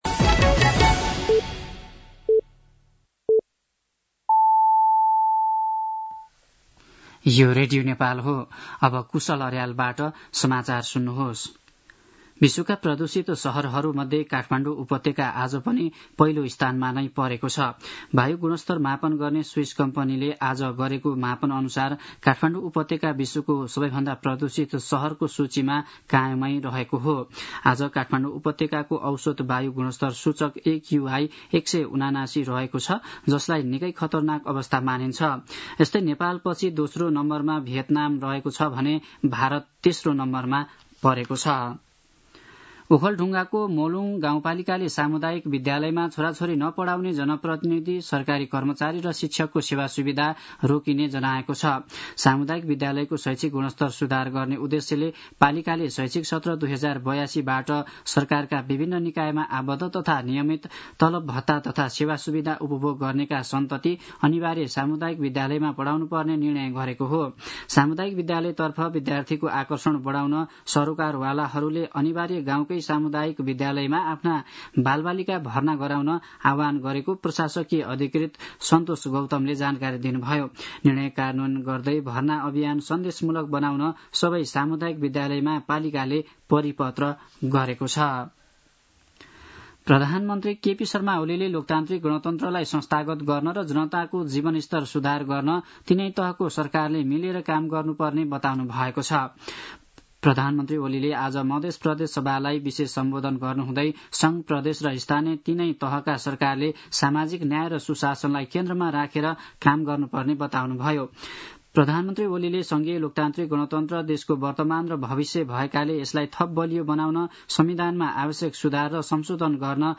दिउँसो ४ बजेको नेपाली समाचार : २६ चैत , २०८१
4-pm-Nepali-News-12-26.mp3